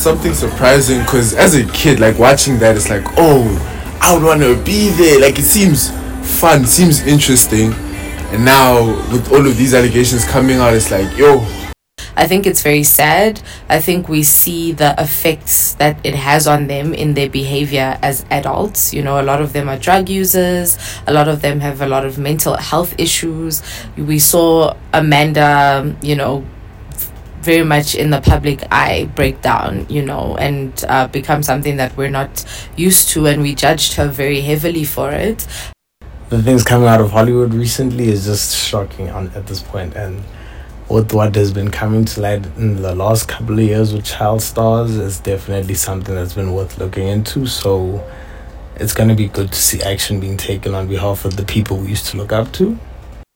The young people YNews spoke to say while the allegations of abuse are shocking, they’re not surprising.
NICKELODEON-COMMENTS.wav